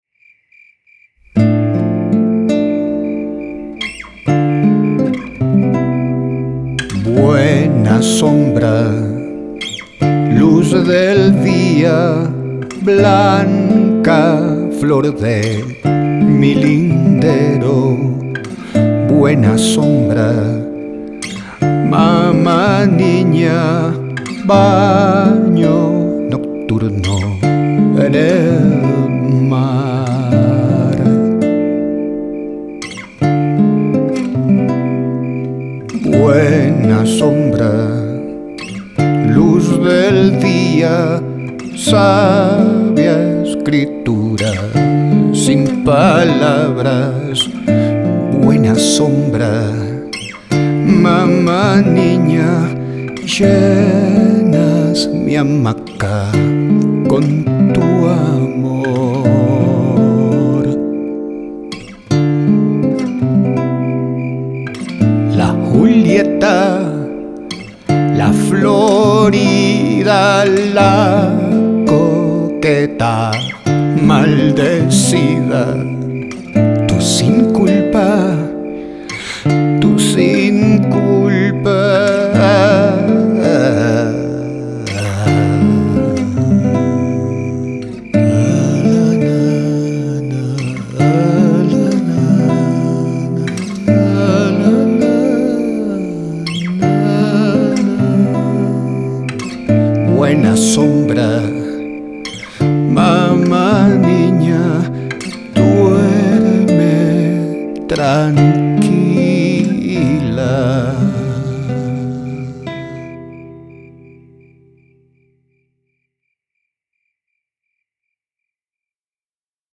guitarra española, voz.
programación de efectos.